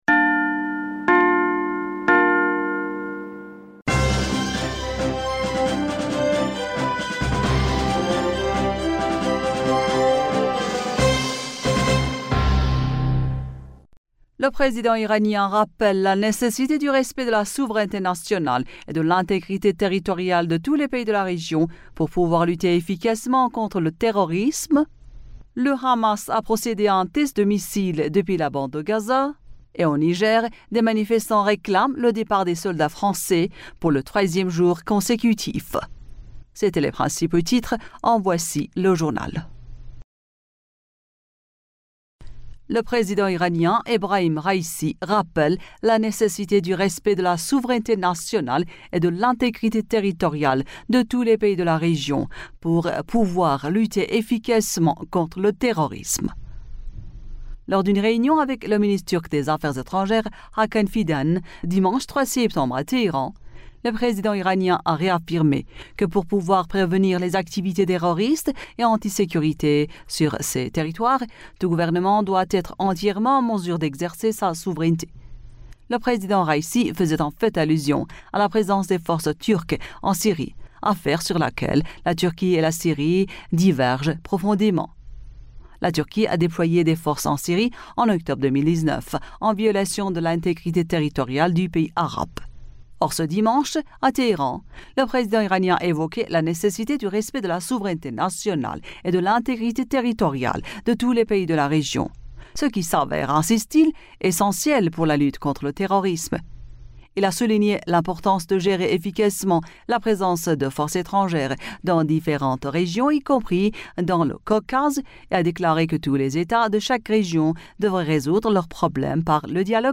Bulletin d'information du 04 Septembre 2023